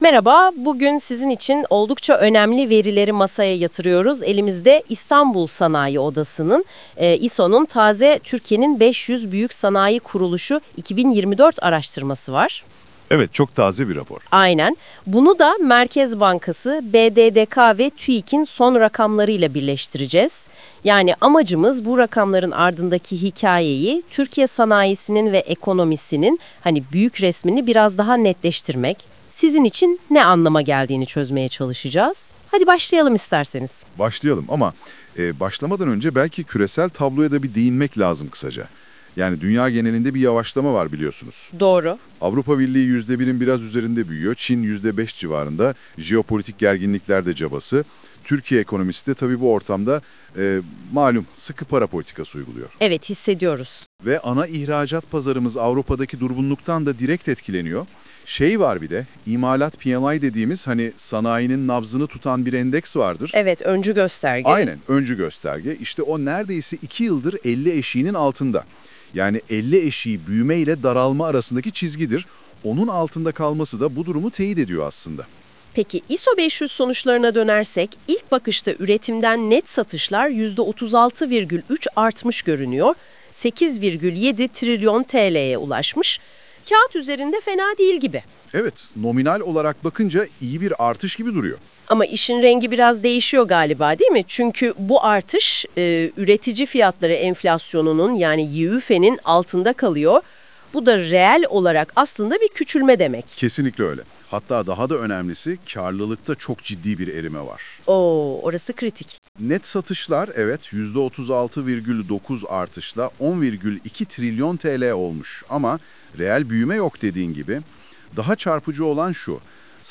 Jeopolitik gelişmeler, finansal haberler ve ekonomik verileri içeren TEMA GRUP HAFTALIK FİNANS VE EKONOMİ BÜLTENİ’ne buradan ulaşabilirsiniz. Yapay zeka AI ile yapılan sesli versiyonu